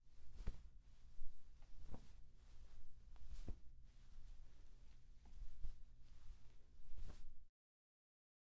描述：挂夹克
Tag: 悬挂 夹克